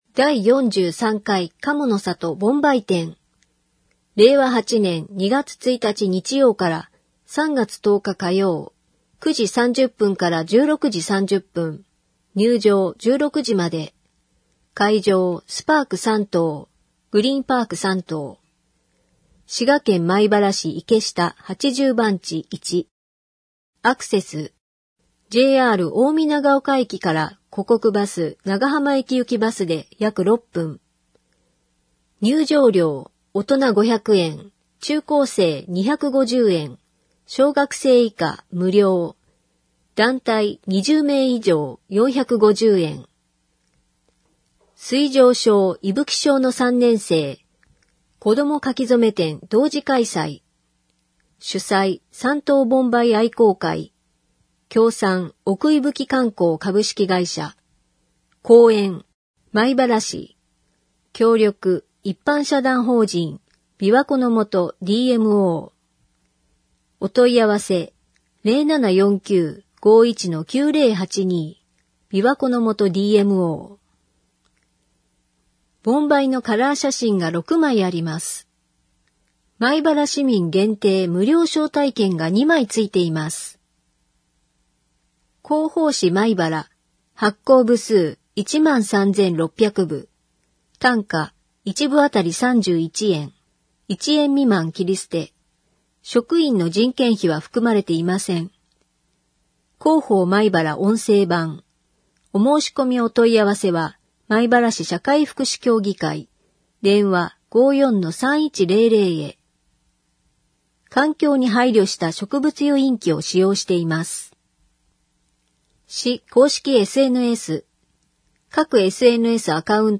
障がい者用に広報まいばらを音訳した音声データを掲載しています。
音声データは音訳グループのみなさんにご協力いただき作成しています。